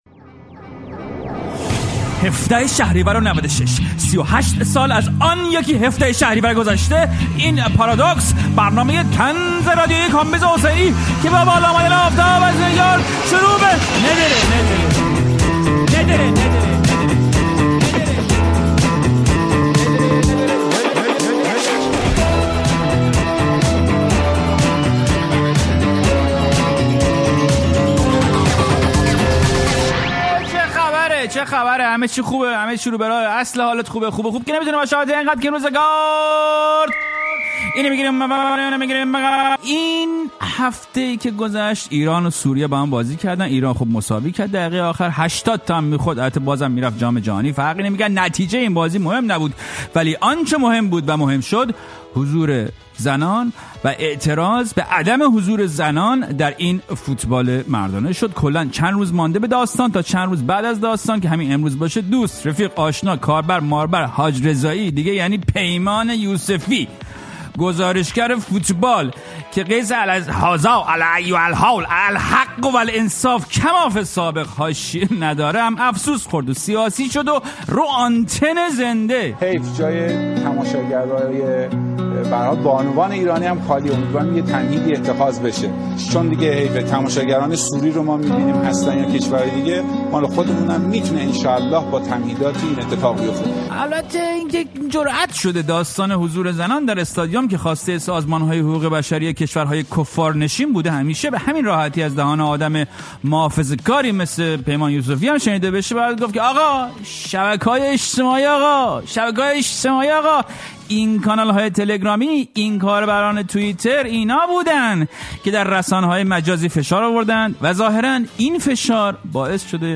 پارادوکس با کامبیز حسینی؛ گفت‌وگو